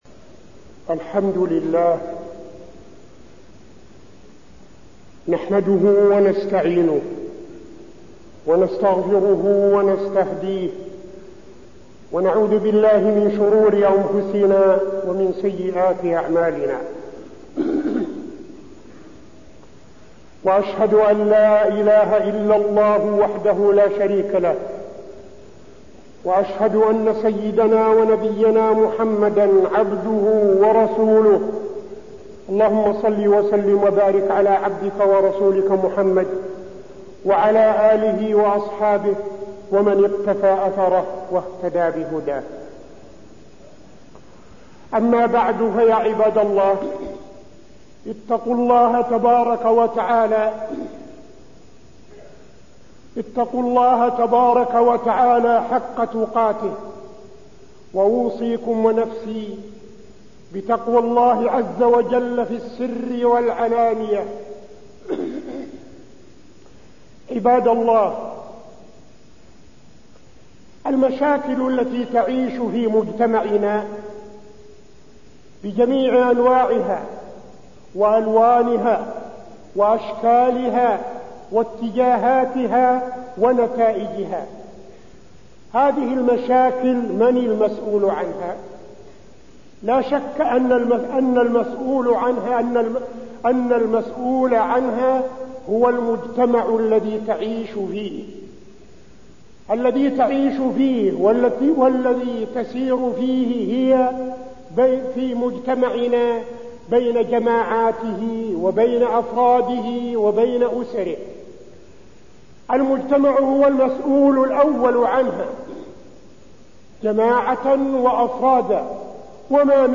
تاريخ النشر ١٠ جمادى الآخرة ١٤٠٥ هـ المكان: المسجد النبوي الشيخ: فضيلة الشيخ عبدالعزيز بن صالح فضيلة الشيخ عبدالعزيز بن صالح مشاكل المجتمع